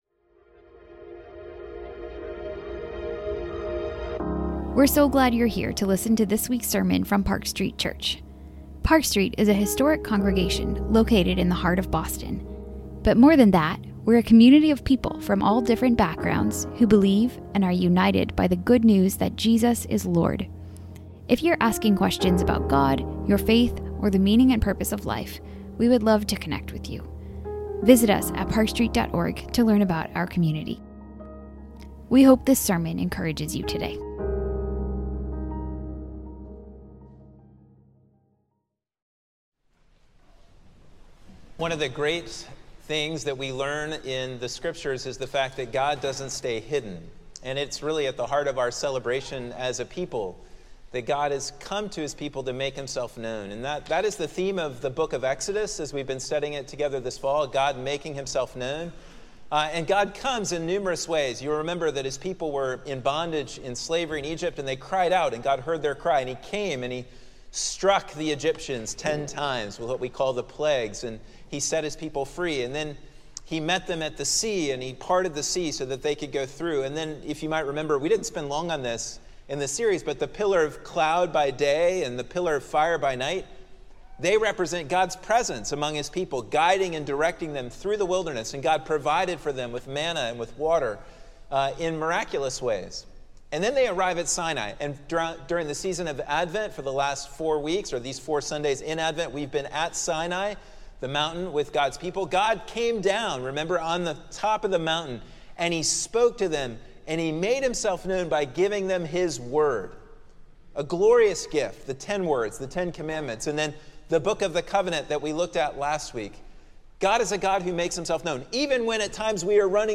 Sermons | Park Street Church